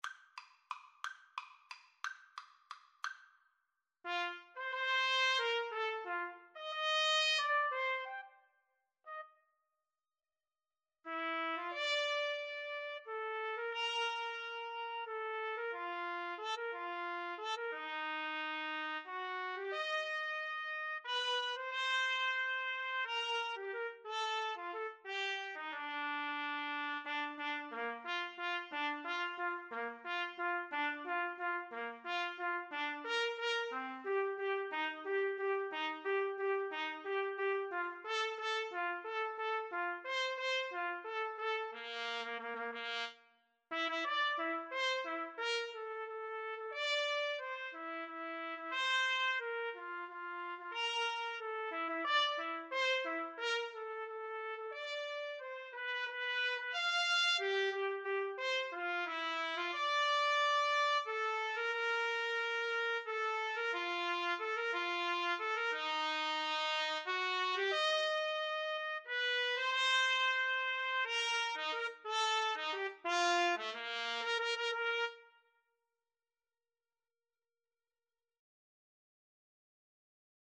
~ = 180 Tempo di Valse
3/4 (View more 3/4 Music)
Classical (View more Classical Trumpet Duet Music)